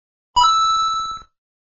SFX马里奥撞击金币素材音效下载